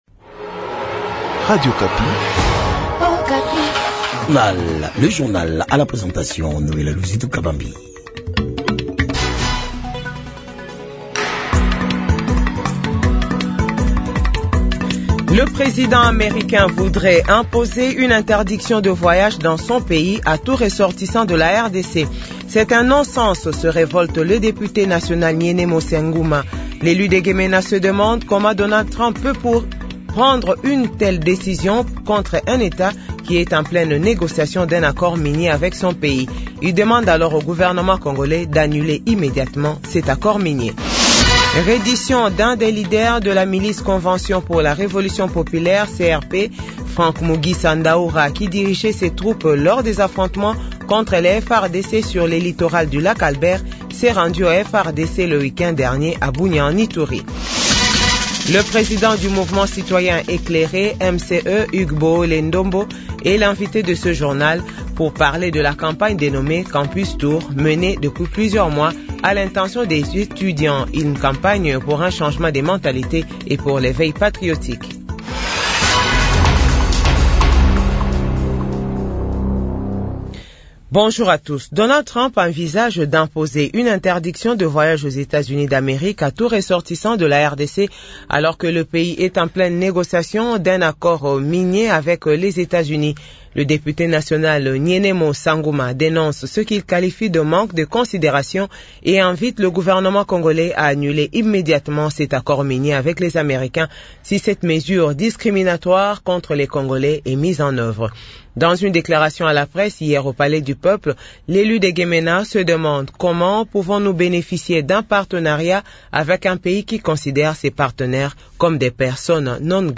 Journal 8h